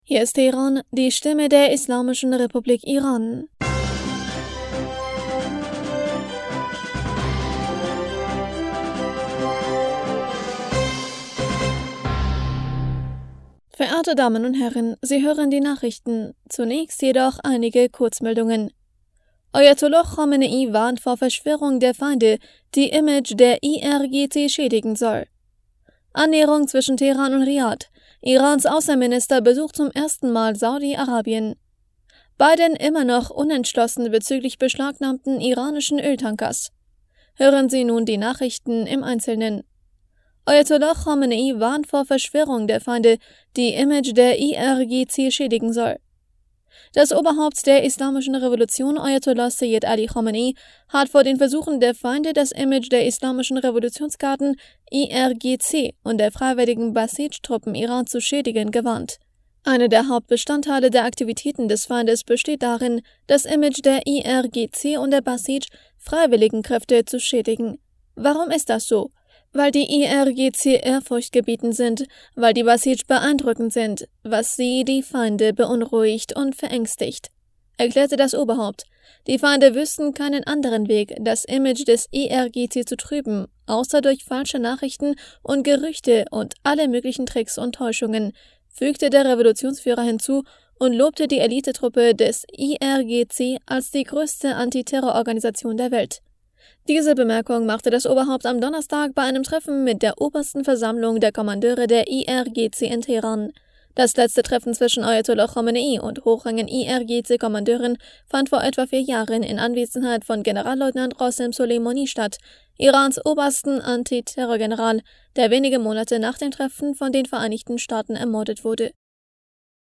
Nachrichten vom 17. August 2023
Die Nachrichten von Donnerstag, dem 17. August 2023